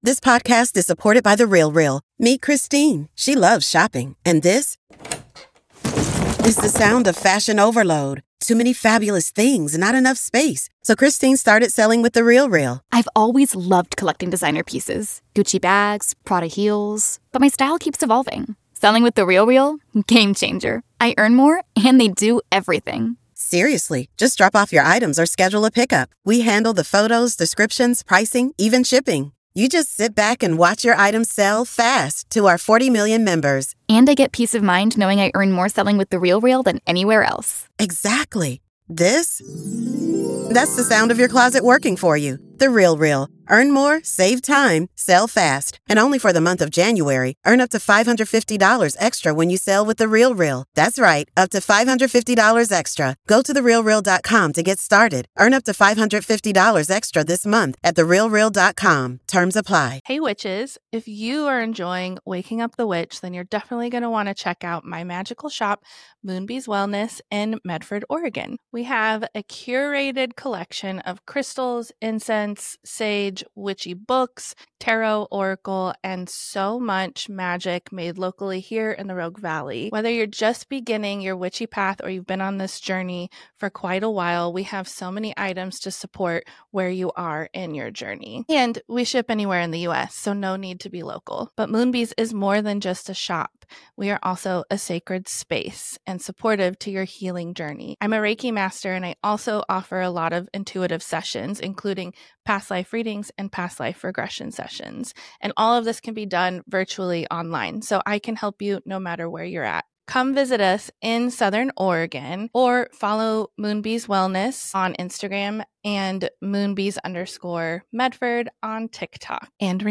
Comedy Interviews